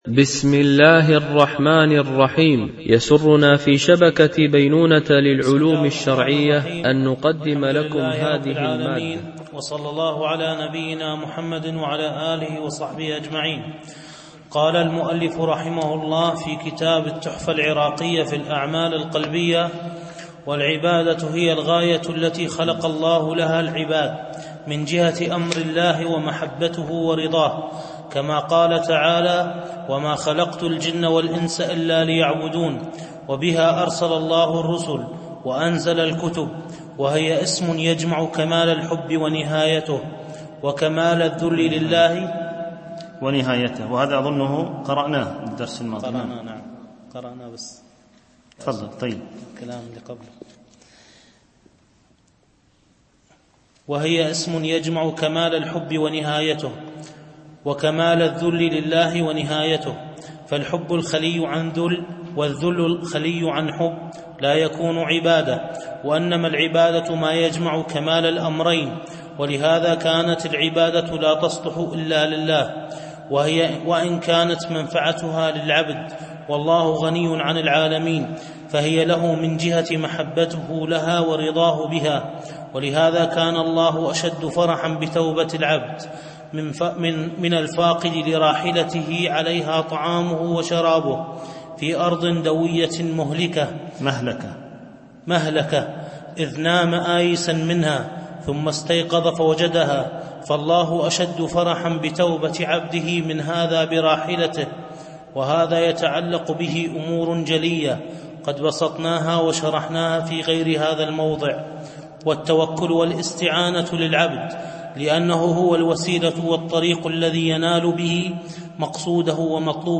الألبوم: دروس مسجد عائشة (برعاية مركز رياض الصالحين ـ بدبي)
التنسيق: MP3 Mono 22kHz 32Kbps (CBR)